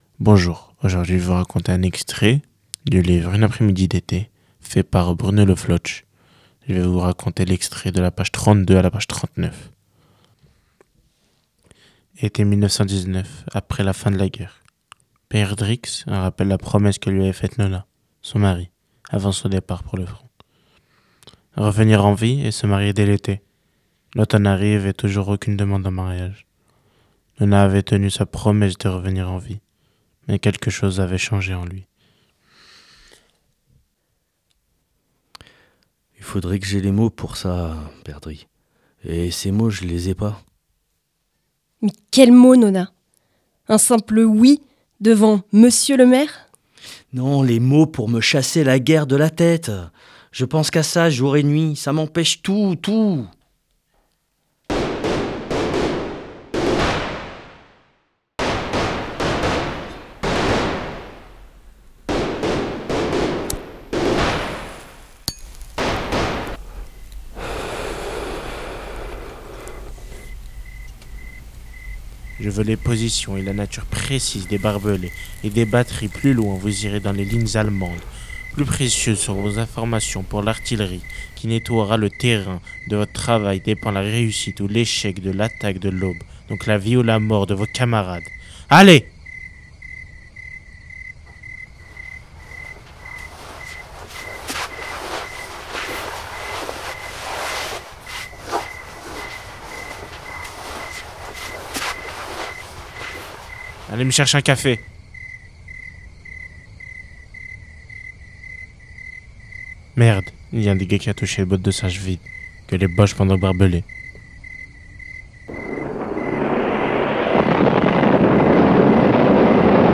Emission - Radio Écoles BD une après-midi d’été Publié le 15 janvier 2026 Partager sur… Télécharger en MP3 Création sonore d’un extrait de la bande dessinée “ Une après-midi d’été” L’occasion de vous faire découvrir ou de redécouvrir cette bande dessinée.